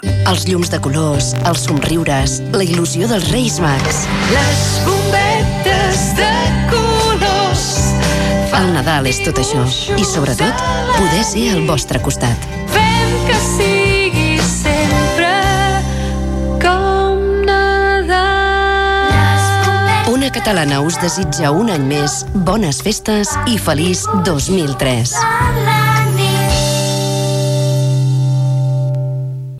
Indicatiu i felicitació Nadal
Banda FM